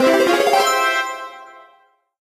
reveal_common_card_01.ogg